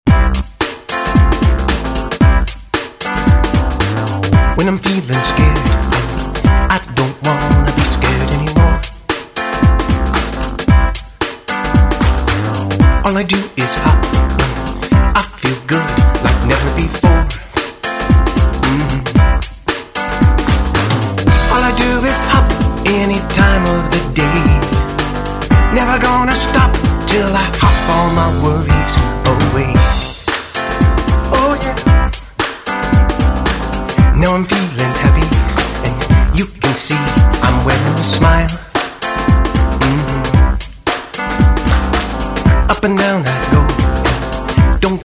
kid funk, blues, pop, rock, country, anthem and ballad